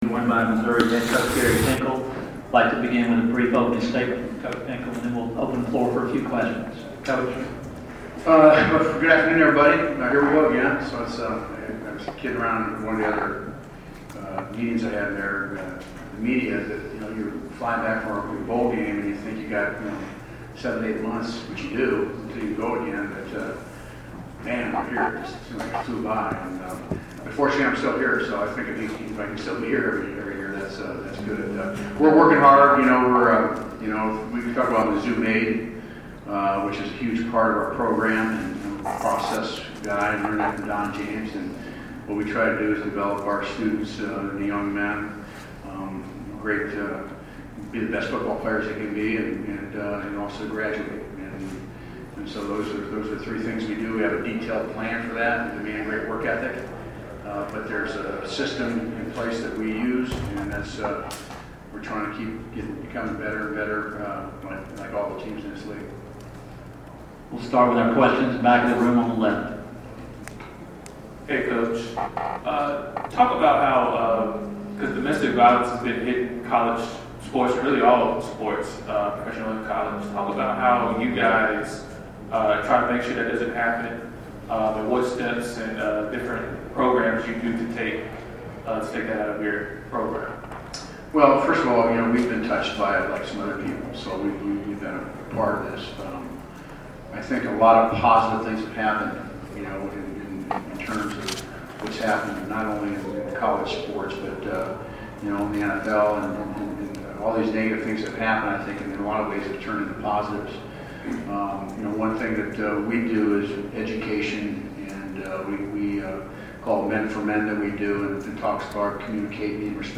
Missouri head coach at SEC Media Days 2015